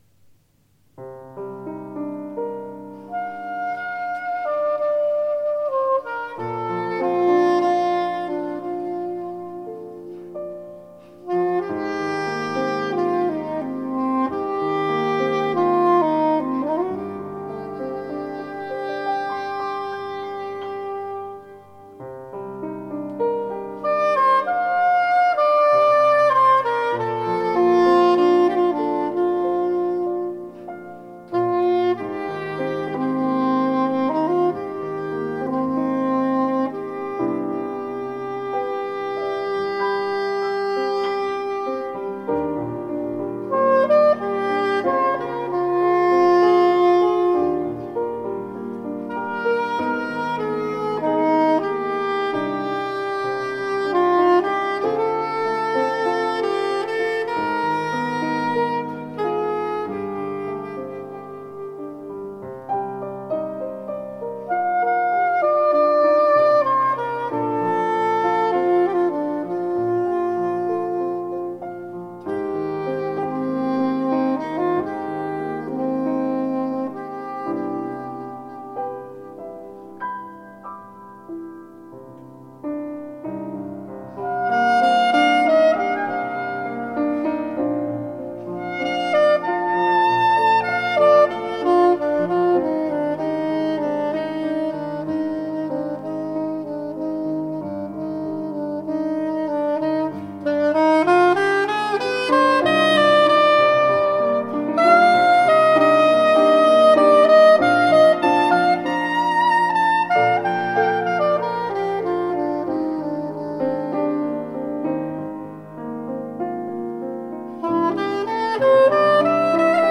improvisieren über Melodien aus Jazz und Pop
Leises und Lautes und Lieblingsstücke.
live in der Frauenkirche Dresden